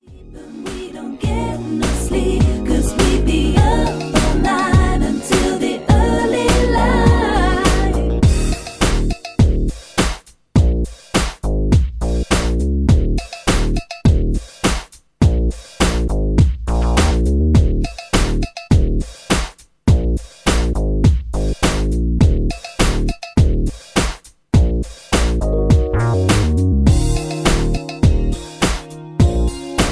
Karaoke MP3 Backing Tracks
mp3 backing tracks